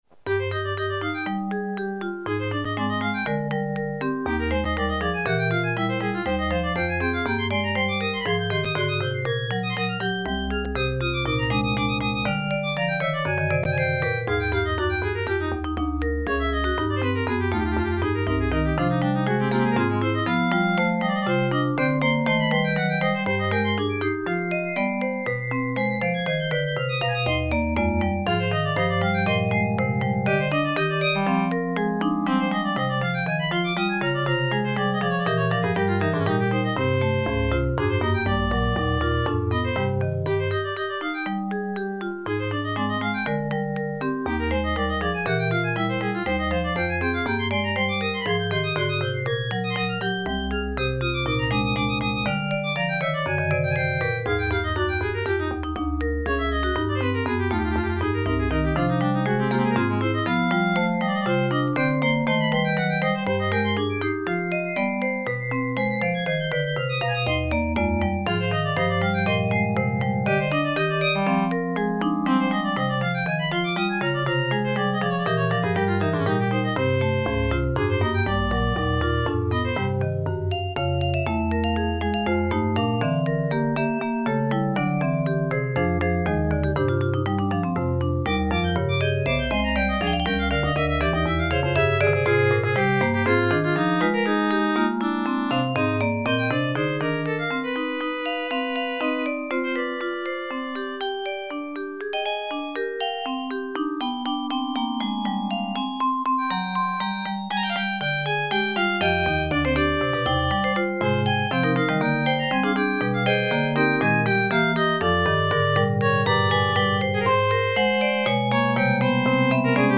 POLYPHONIC MUSIC
divertimento-para-violino-e-vibrafone.mp3